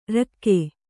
♪ rakke